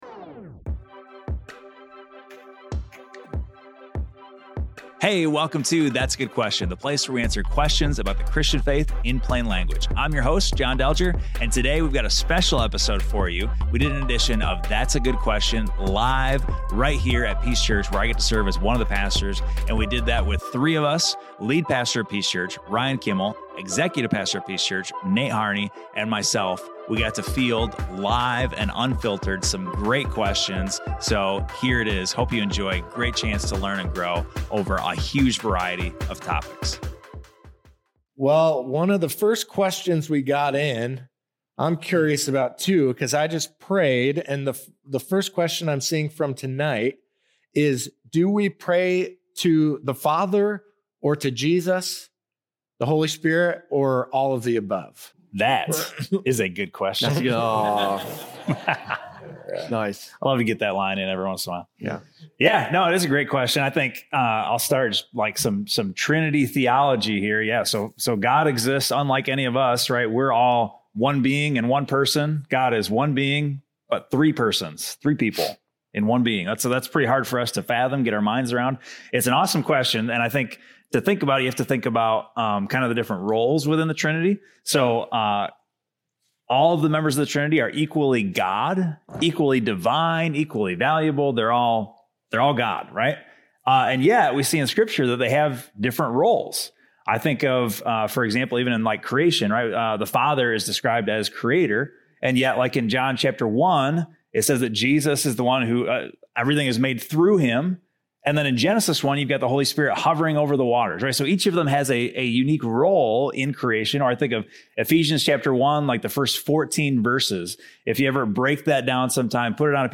field live questions diving into the tension Christians face when their convictions collide with the world around them.